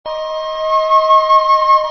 flute nș 8
Flute8.mp3